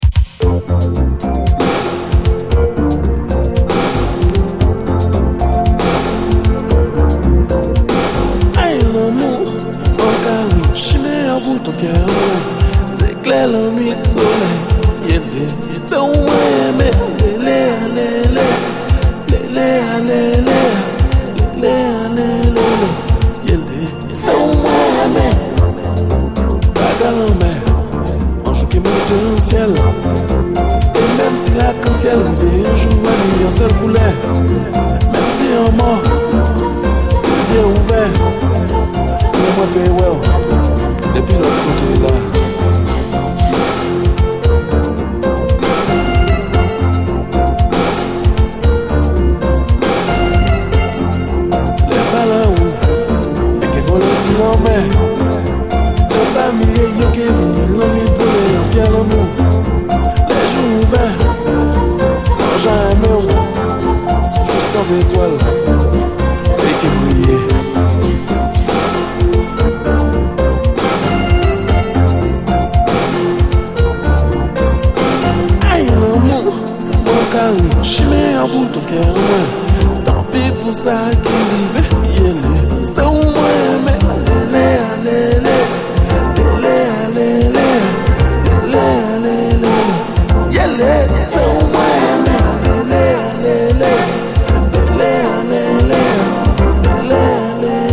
CARIBBEAN
シルキーなサウンドの80'Sアフロ・フレンチ・カリビアン！
リゾート感溢れるメロウ・カリビアン